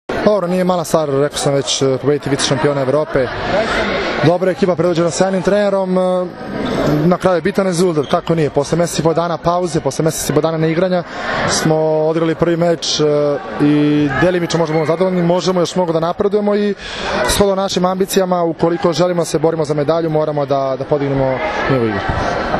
IZJAVA ALEKSANDRA ATANASIJEVIĆA